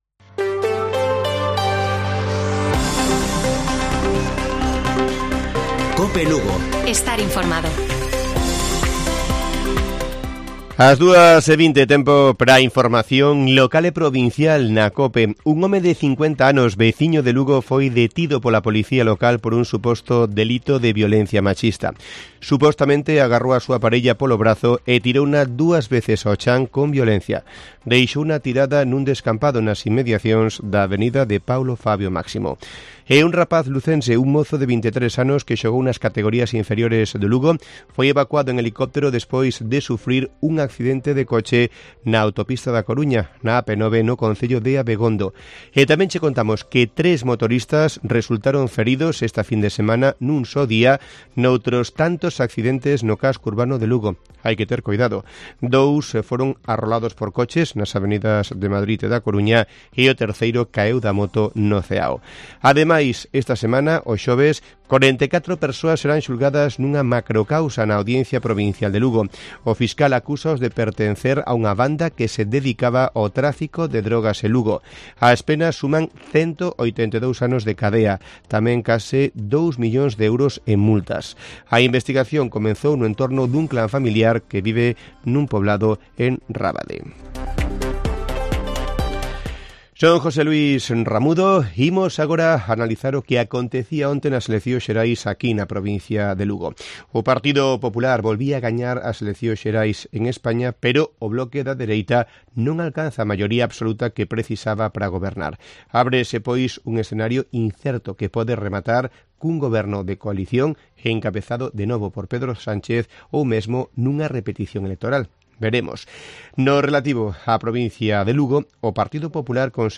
Informativo Mediodía de Cope Lugo. 24 de julio . 14:20 horas